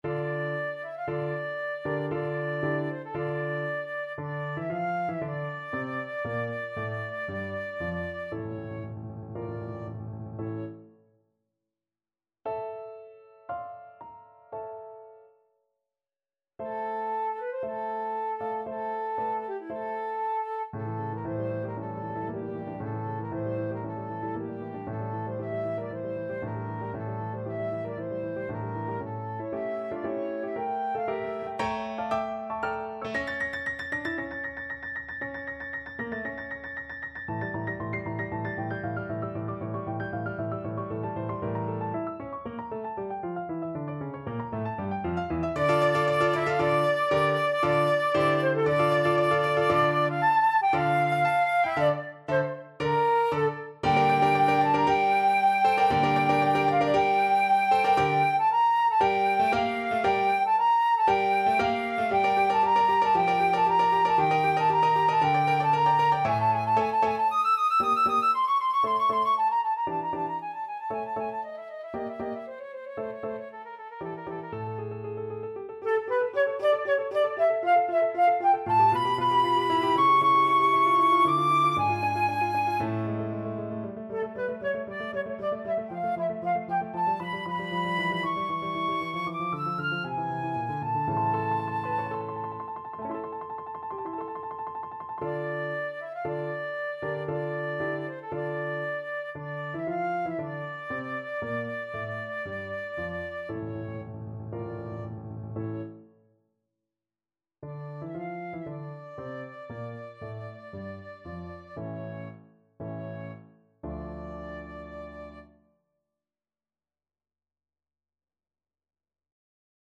Classical Dvořák, Antonín Concerto for Cello Op.104, 1st Movement Main Theme Flute version
D minor (Sounding Pitch) (View more D minor Music for Flute )
Allegro =116 (View more music marked Allegro)
4/4 (View more 4/4 Music)
E5-F7
Instrument:
Classical (View more Classical Flute Music)
dvorak_cello_concerto_1st_main_FL.mp3